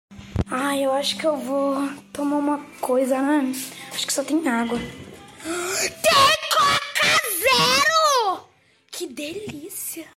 tem coca zero que delicia Meme Sound Effect